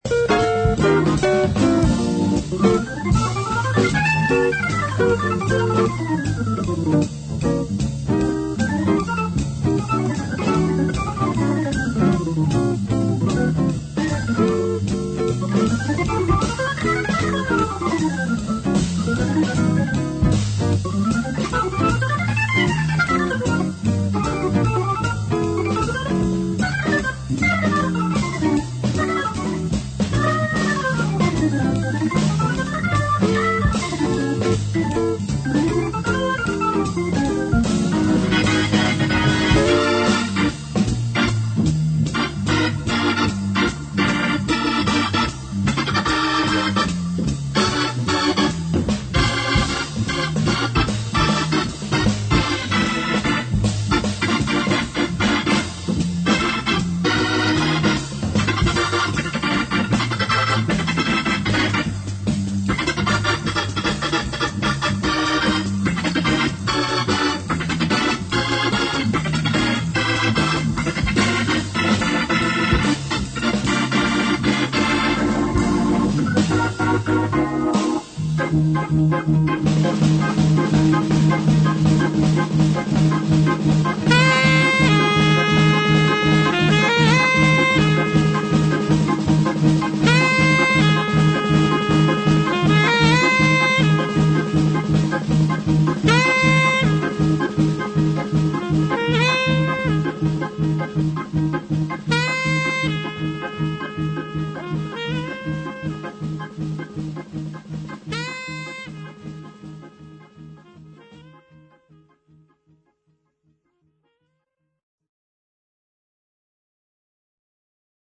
un autre extrais avec un passage en full terrible
on entend bien le tchou-tchou qui s'éloigne !